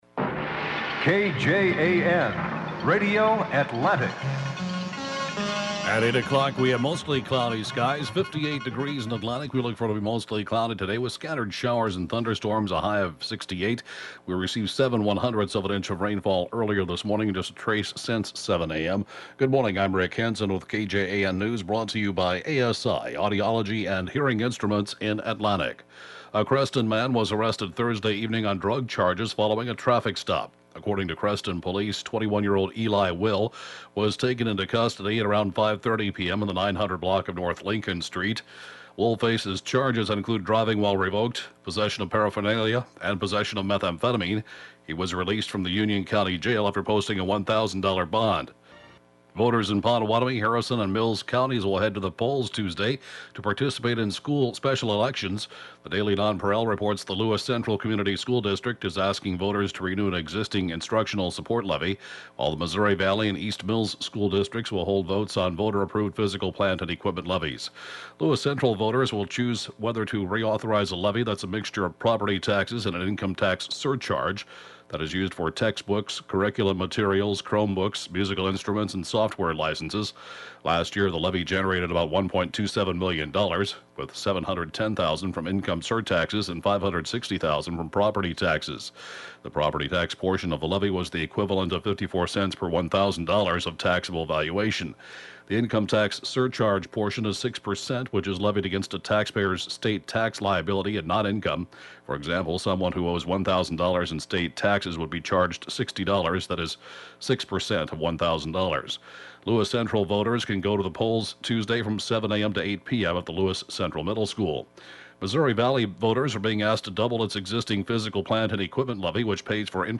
KJAN News